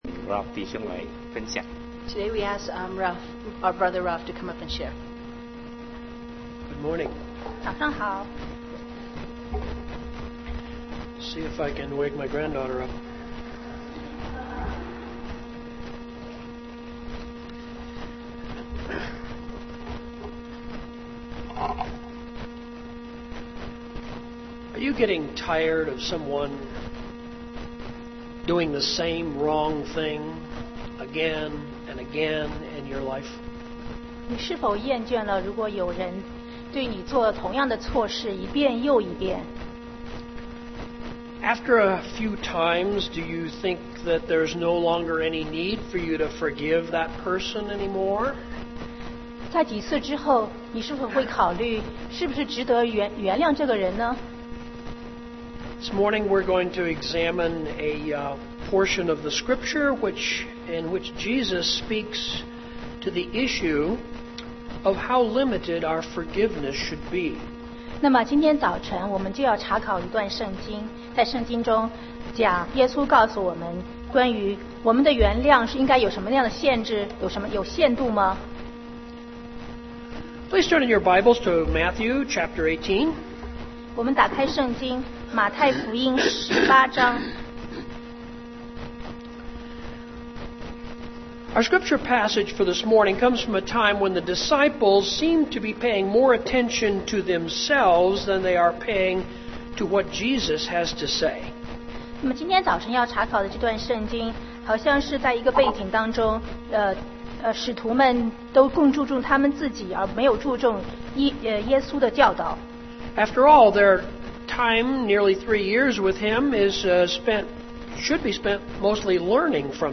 Sermon 2010-11-14 The Parable of the Unforgiving Servant